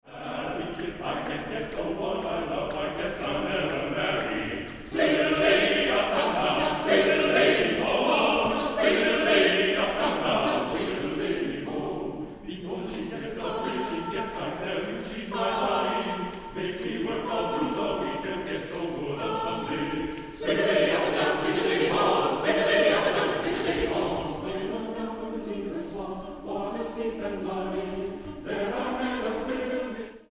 Instrumentation: SATB chorus